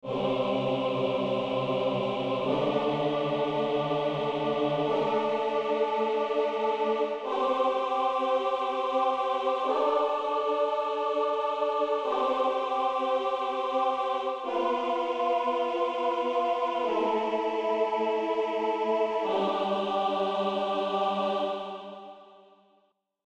Tritono.mp3